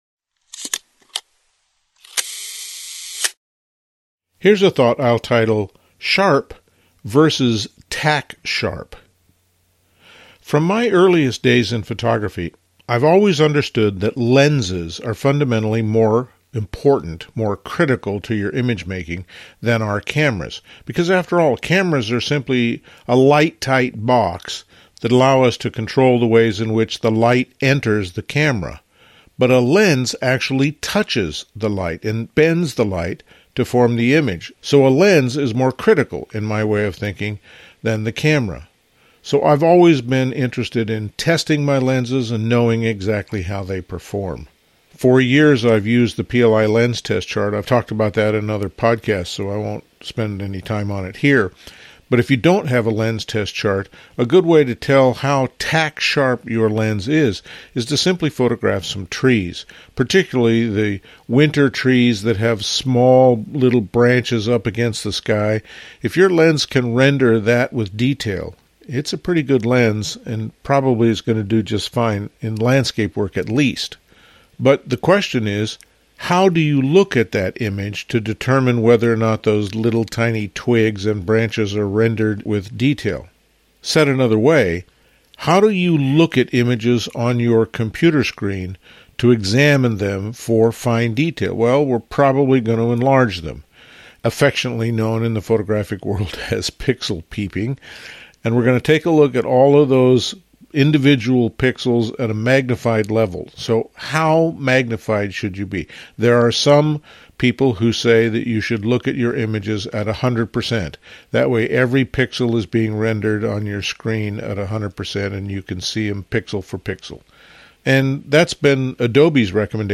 These talks focus on the creative process in fine art photography.